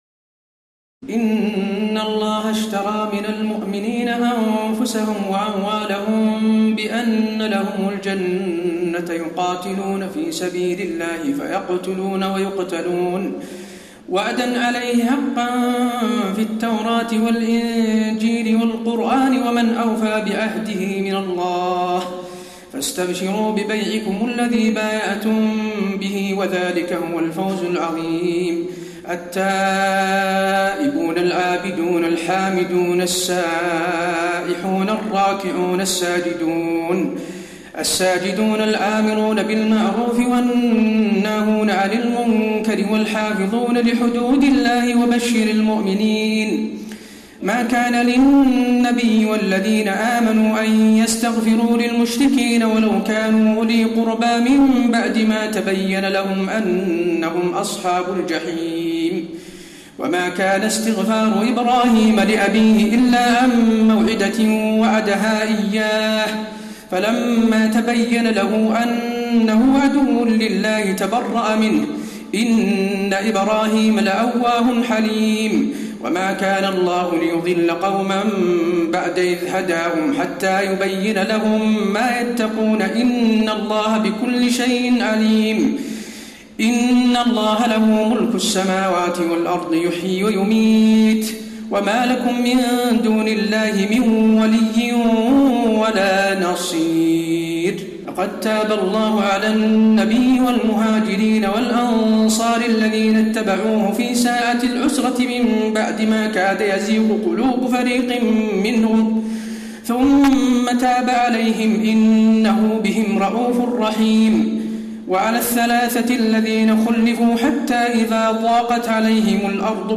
تراويح الليلة الحادية عشر رمضان 1432هـ من سورتي التوبة (111-129) و يونس (1-61) Taraweeh 11 st night Ramadan 1432H from Surah At-Tawba and Yunus > تراويح الحرم النبوي عام 1432 🕌 > التراويح - تلاوات الحرمين